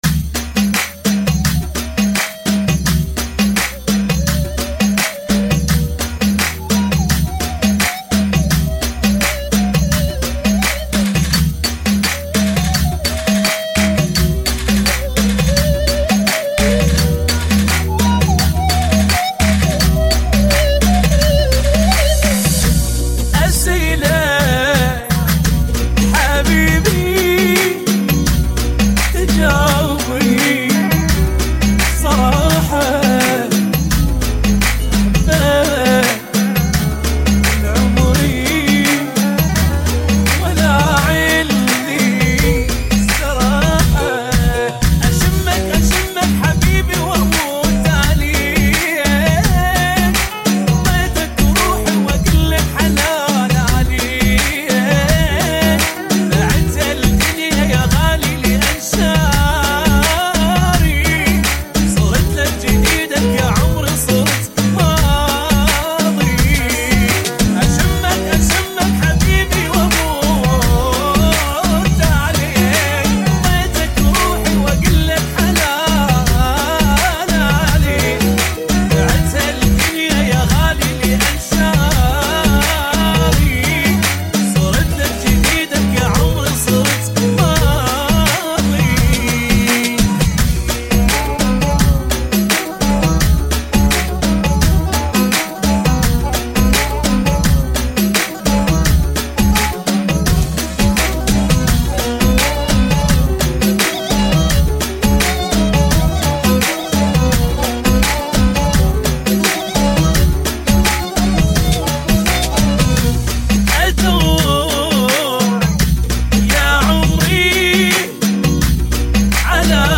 85 Bpm